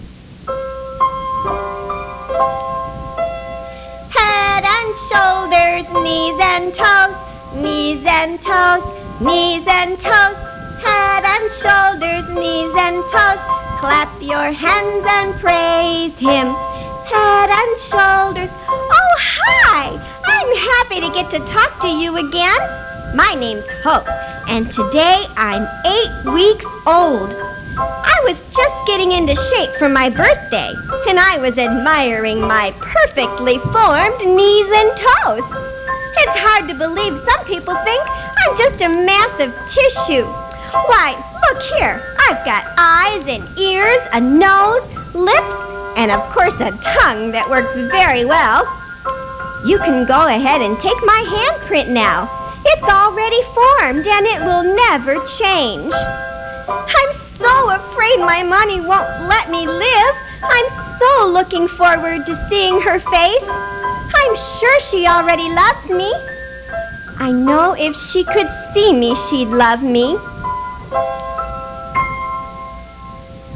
Baby Hope was originally created as commercials for a pro-life rally aired on my husband's radio program. I researched the development of a pre-born baby month by month, then became the"voice" of that baby.